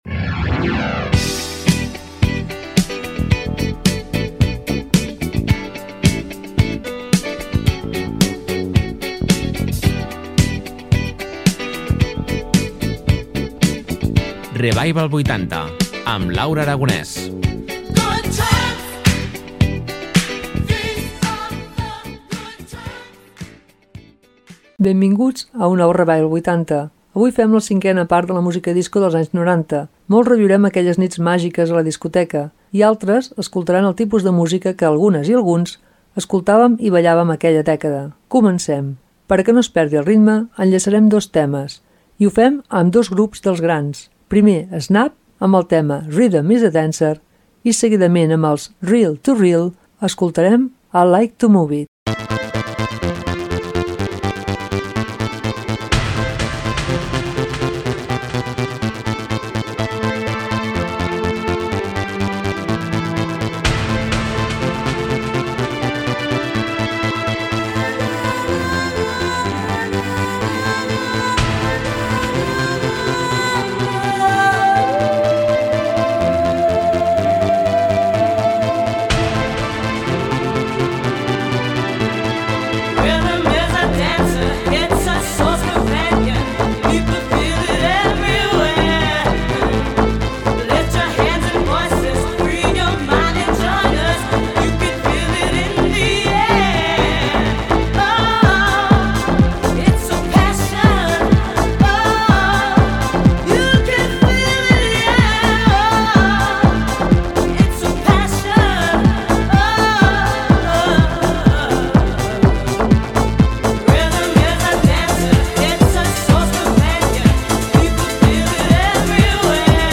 En el Revival 80 d'avui fem una 5ª part del programa dedicat a la música disco dels 90's. Tornareu a reviure les nits màgiques de les pistes de ball.